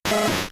Cri de Rattata K.O. dans Pokémon Diamant et Perle.